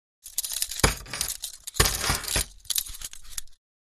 Prisoner-handcuffs-movement-handling-rattle-3.mp3